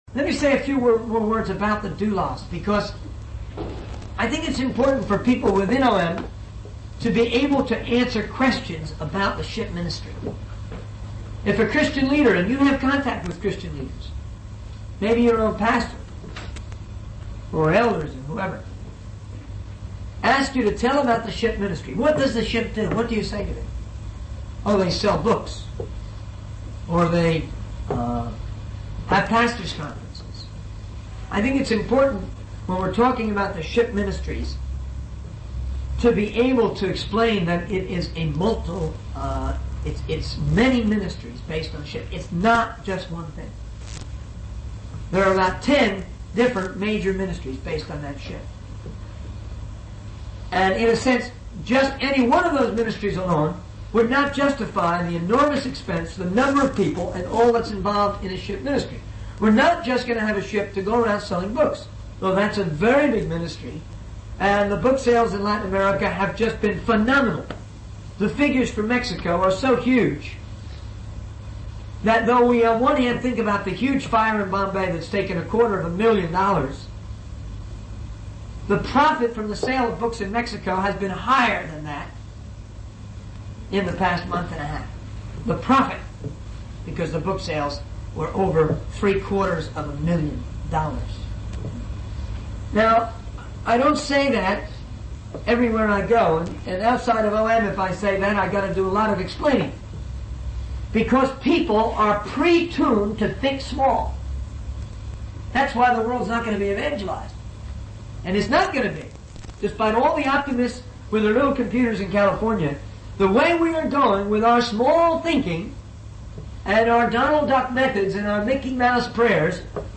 In this sermon, the speaker shares his experience with a close friend who showed him the incredible capabilities of a computer. He also mentions the success of the book exhibition ministry, which has planted Christian books in Mexico.